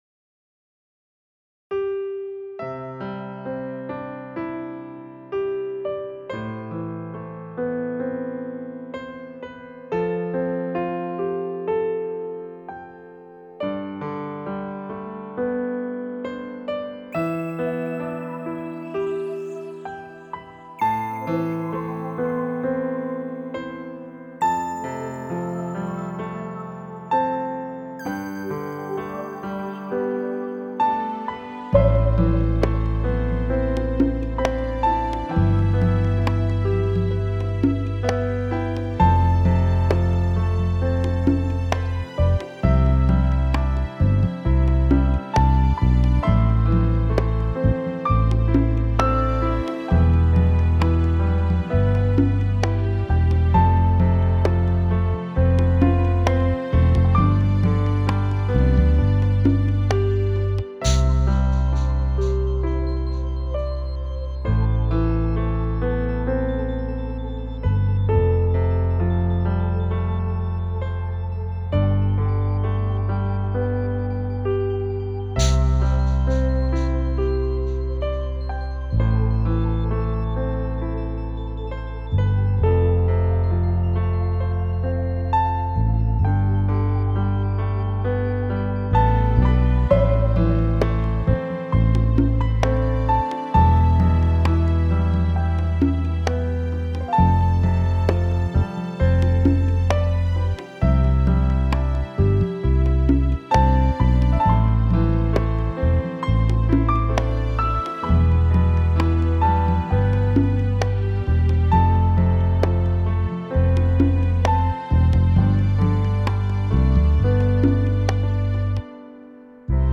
钢琴